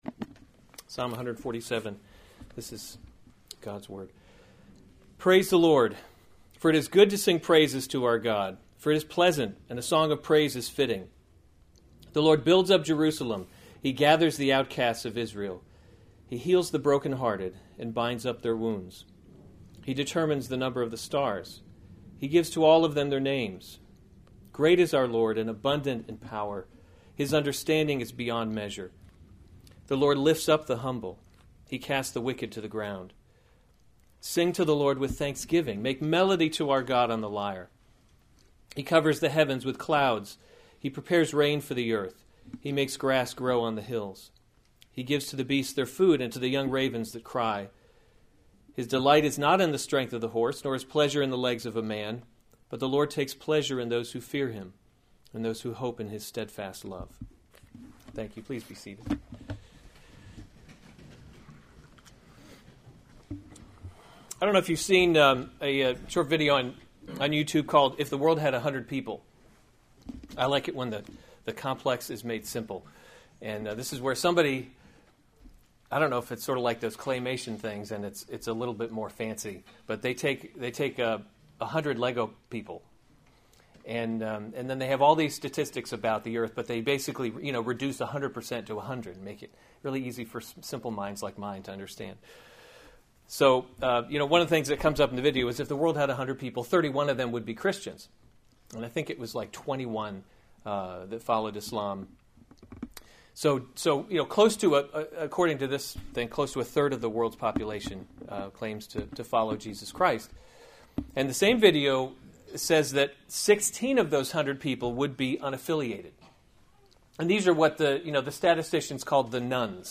September 3, 2016 Psalms – Summer Series series Weekly Sunday Service Save/Download this sermon Psalm 147:1-11 Other sermons from Psalm He Heals the Brokenhearted 147:1 Praise the Lord!